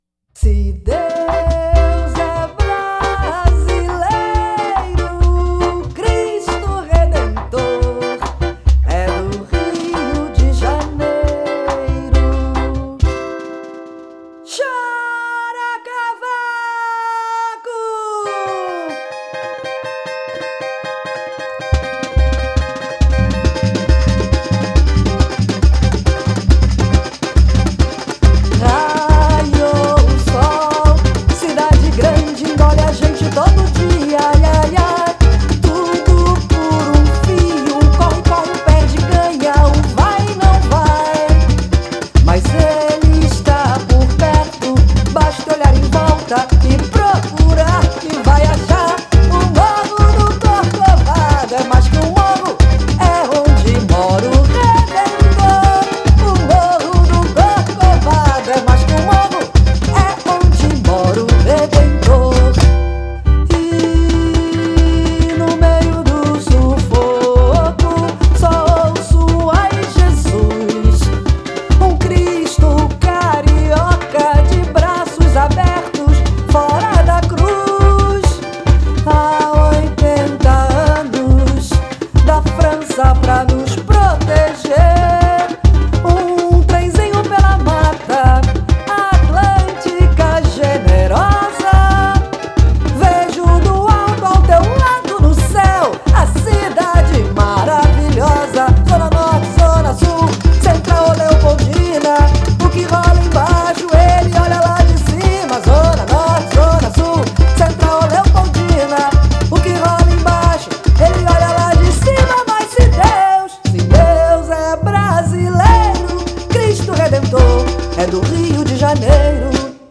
SAMBA ENREDO 2012